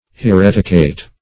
Search Result for " hereticate" : The Collaborative International Dictionary of English v.0.48: Hereticate \He*ret"i*cate\, v. t. [LL. haereticatus, p. p. of haereticare.] To decide to be heresy or a heretic; to denounce as a heretic or heretical.